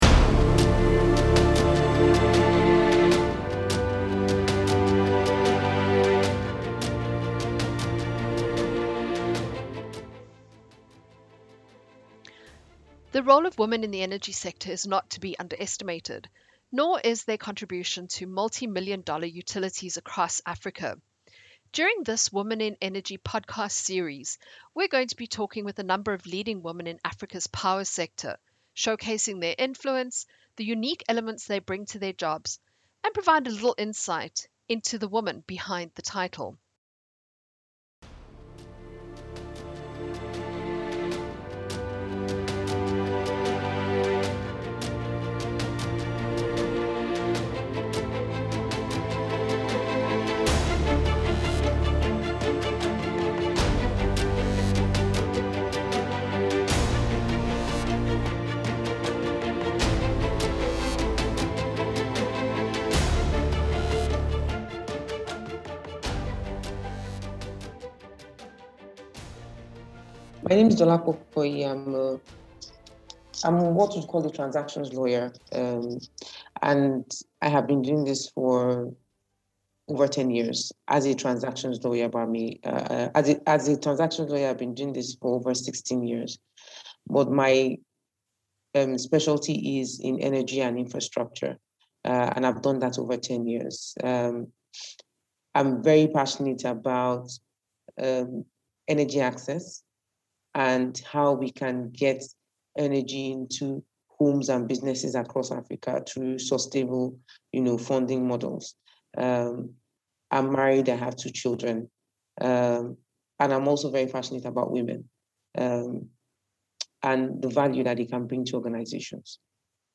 Women in Energy: An interview